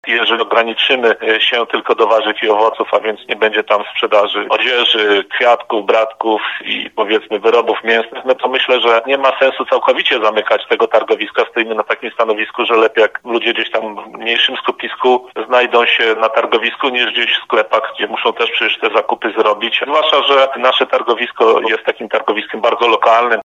Mówił burmistrz Miasta i Gminy Nowa Dęba, Wiesław Ordon.